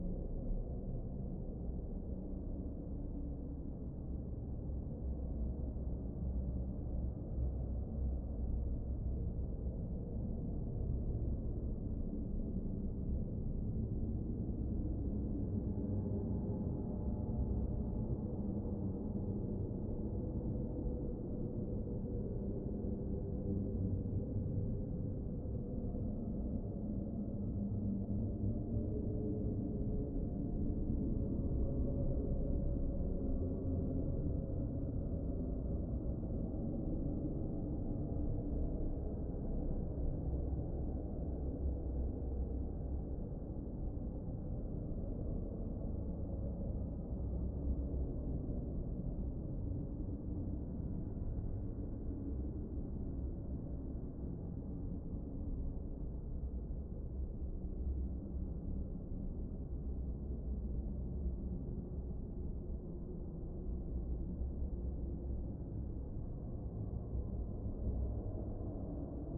horror ambience 16
afraid ambiance ambience ambient anxious atmo atmos atmosphere sound effect free sound royalty free Nature